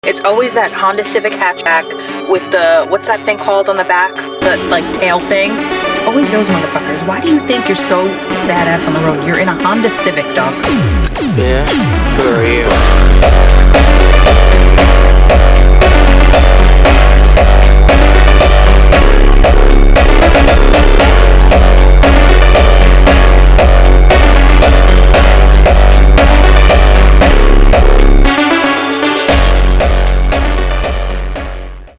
GTR Vs Civic FWD Sound Effects Free Download